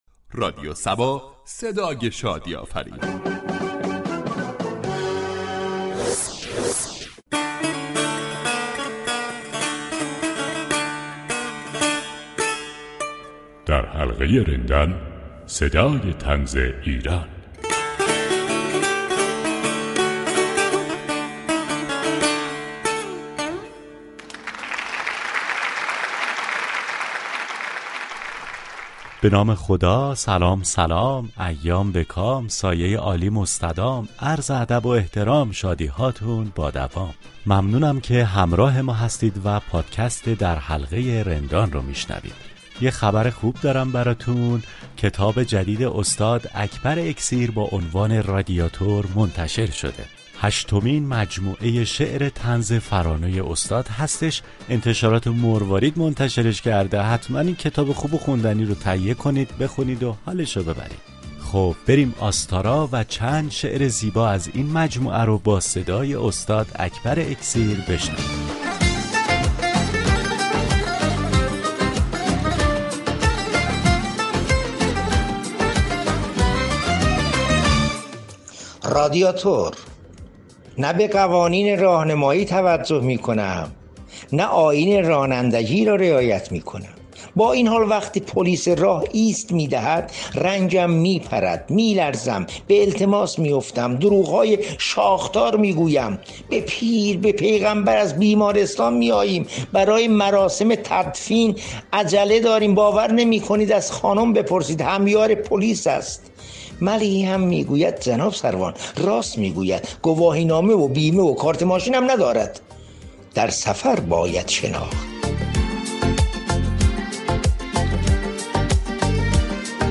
در این برنامه اكبر اكسیر چند شعر طنز از تازه‌ترین كتاب خود با نام رادیاتور و چند شعر طنز از مجموعه‌های پیشین خود می‌خواند.